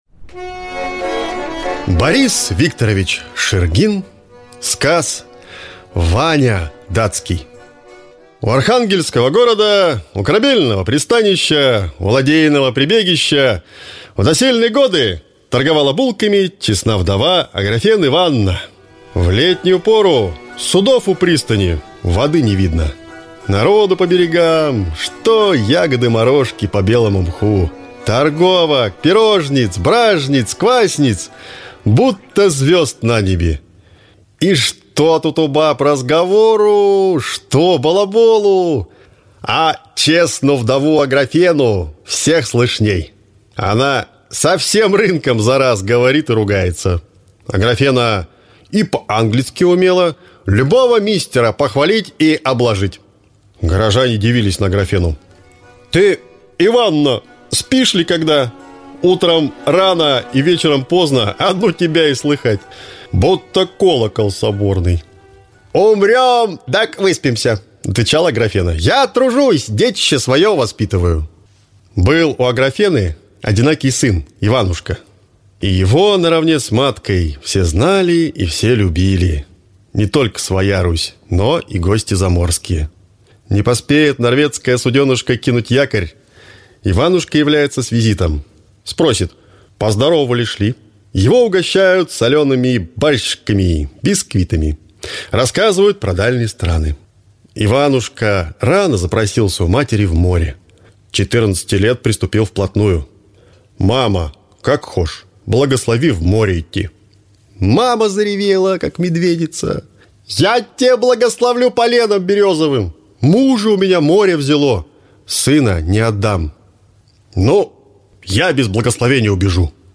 Аудиокнига Русского Севера сказы. Часть 1 | Библиотека аудиокниг